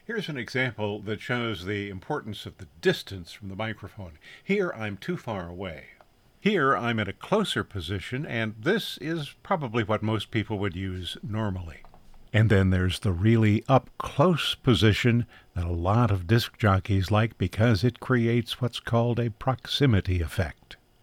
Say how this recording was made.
When I moved closer to the microphone, both background noise and echo were significantly reduced so the optimization process has less to do. The distance between the person speaking and the microphone has a significant effect on the recording. 876-MicProximity.mp3